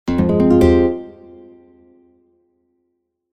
messagealert3.mp3